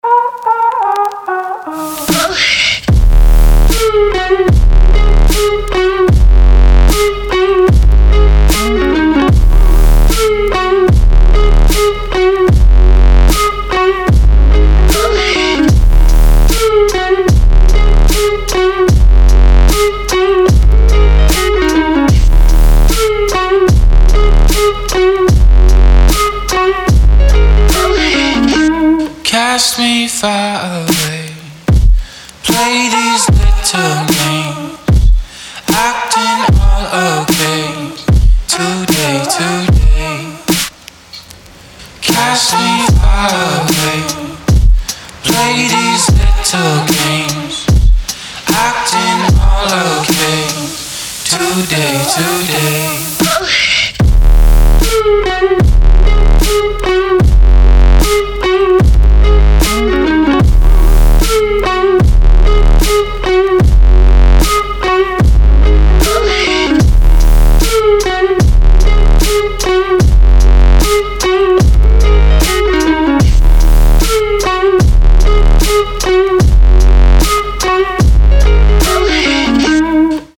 • Качество: 320, Stereo
гитара
ритмичные
громкие
dance
Electronic
мощные басы
чувственные
Bass
alternative